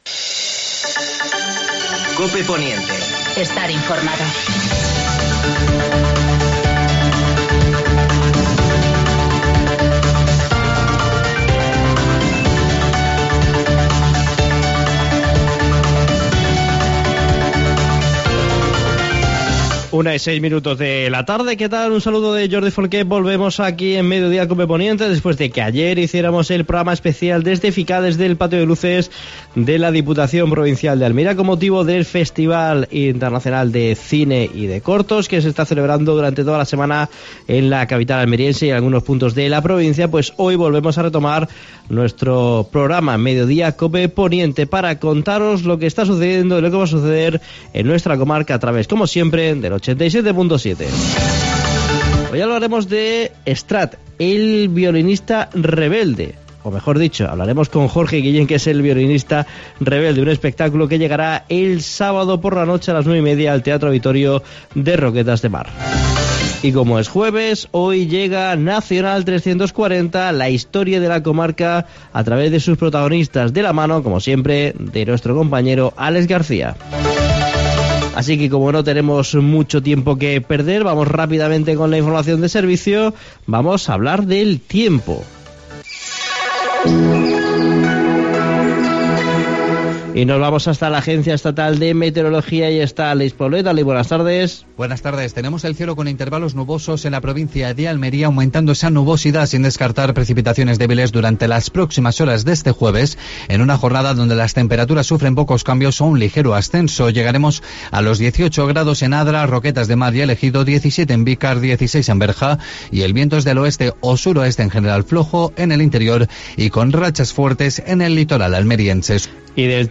AUDIO: Actualidad en el Poniente. Entrevista